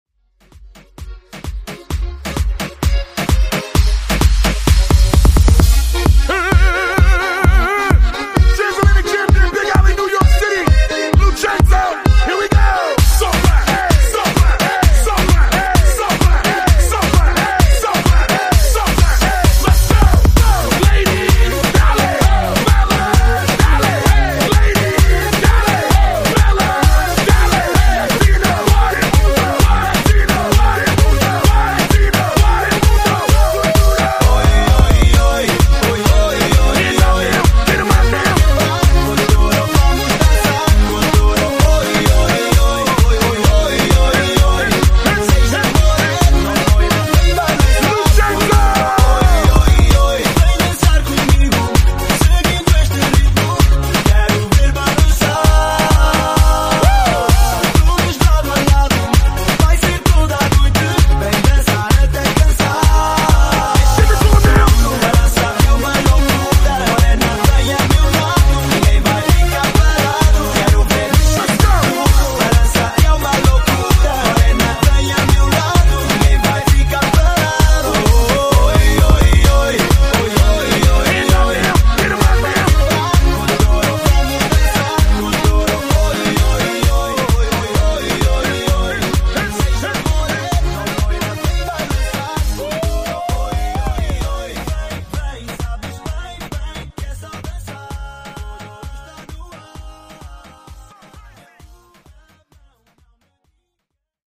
Genres: 2000's , DANCE , RE-DRUM
Clean BPM: 130 Time